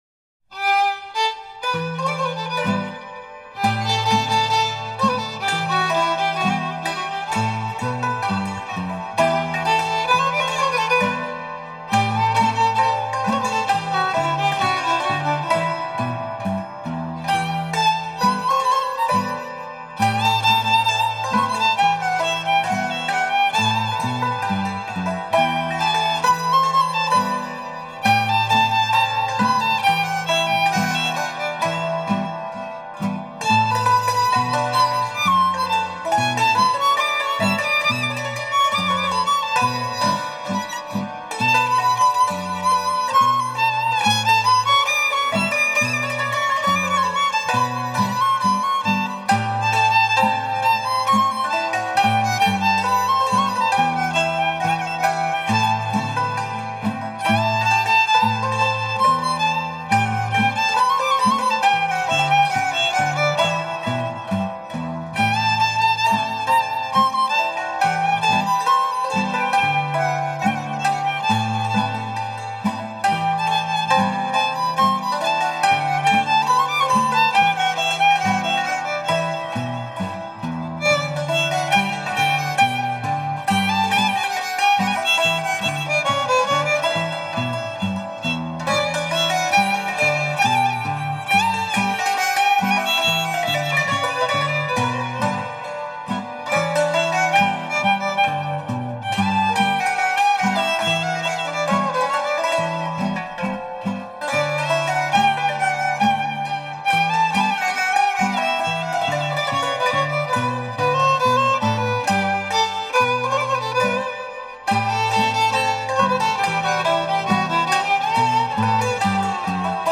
Ζεϊμπέκικο βαρύ Αγιάσος | 1996 ζεϊμπέκικο Παίζουν οι μουσικοί
βιολί
κιθάρα
σαντούρι